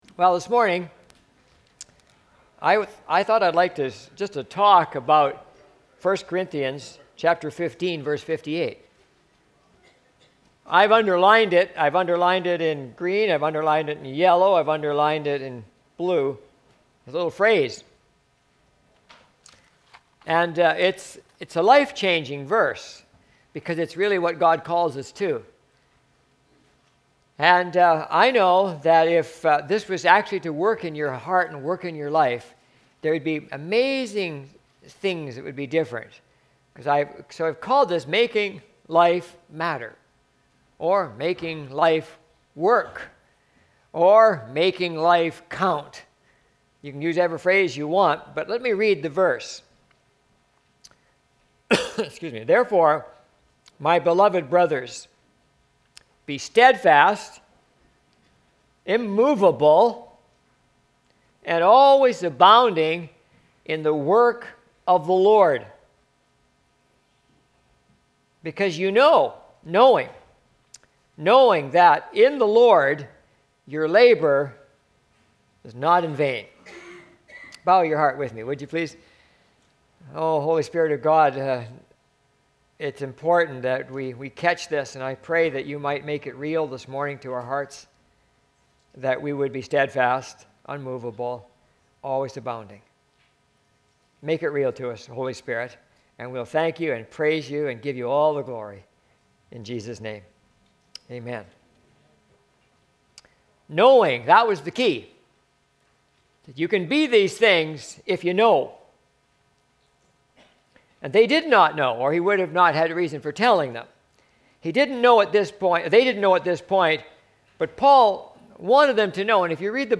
Valley Church - Lynn Valley - North Vancouver - Sermons